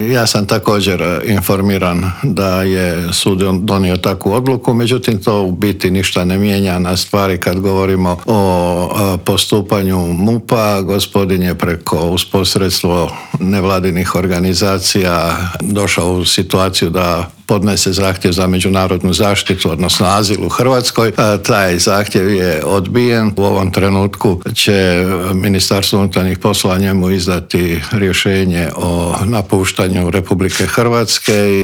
ZAGREB - Nakon dovršenog kriminalističkog istraživanja nad 31-godišnjim državljaninom Alžira, zbog osnova sumnje da je počinio više kaznenih dijela teške krađe, sud ga je pustio da se brani sa slobode, potvrdio nam je ministar unutarnjih poslova Davor Božinović gostujući u Intervjuu tjedna Media servisa.